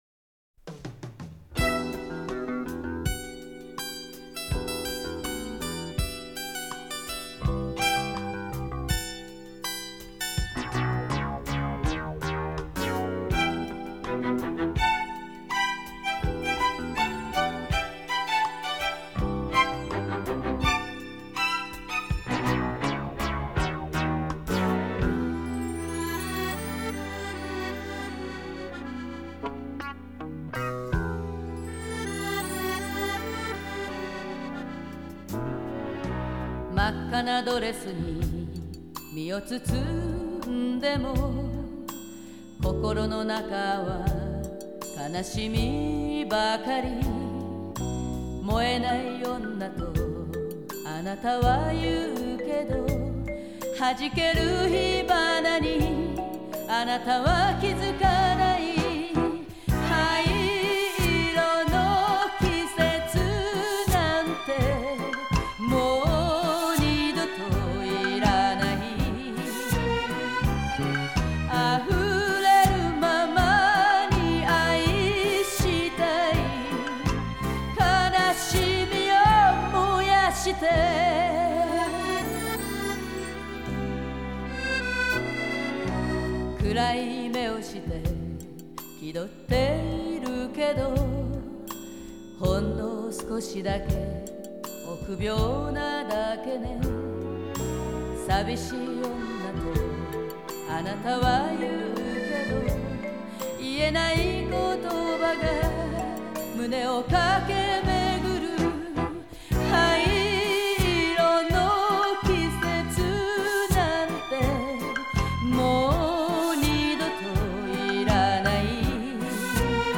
Genre: Japanese Pop